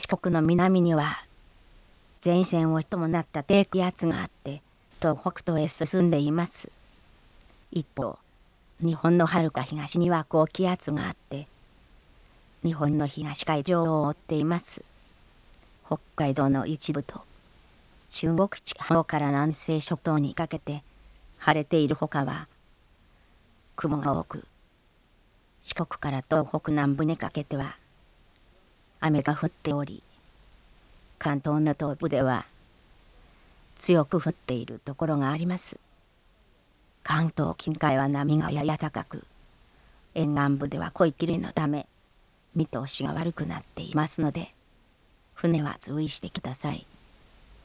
Chatr: a synthesis system